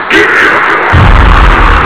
fall2.wav